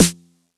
cch_07_snare_one_shot_high_noise_this.wav